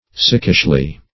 sickishly - definition of sickishly - synonyms, pronunciation, spelling from Free Dictionary
[1913 Webster] -- Sick"ish*ly , adv.